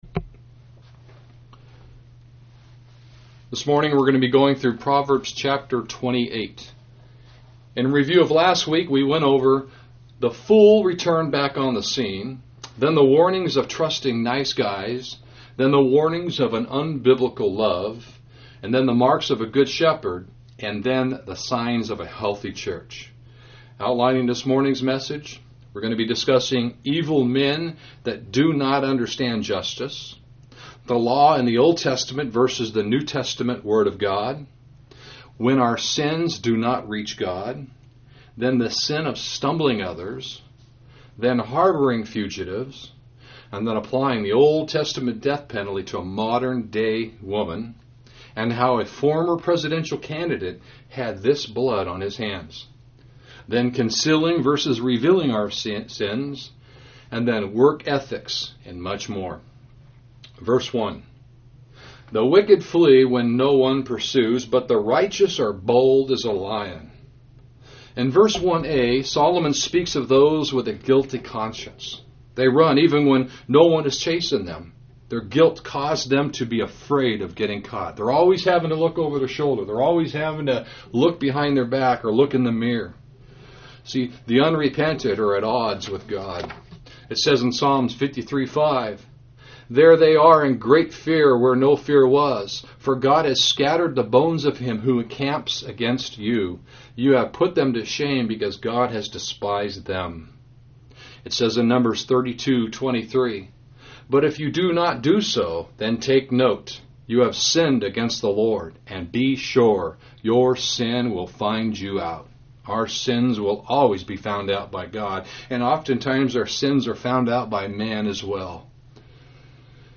A sermon audio of Proverbs Chapter 28 (Harboring fugitives, applying the OT death penalty to a modern day woman, the jihad on 4 American cops, how a former GOP presidential candidate has this homicide blood on his hands)